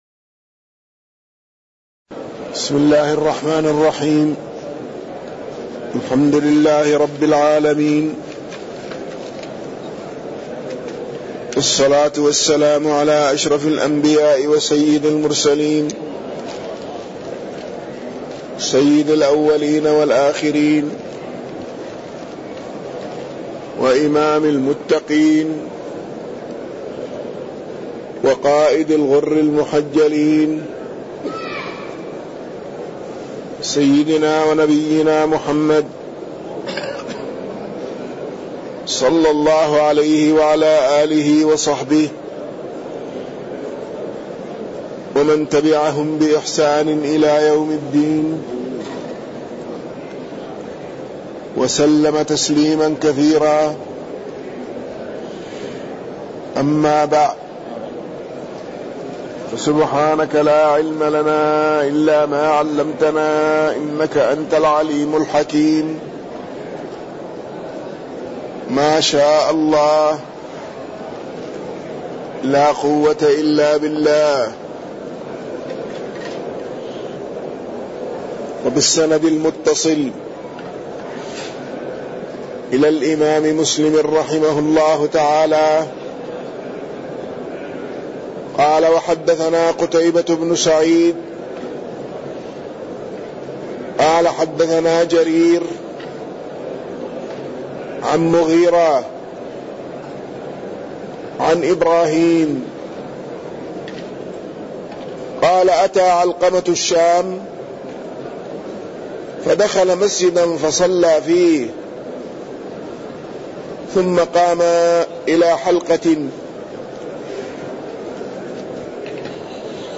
تاريخ النشر ٢٥ جمادى الأولى ١٤٣١ هـ المكان: المسجد النبوي الشيخ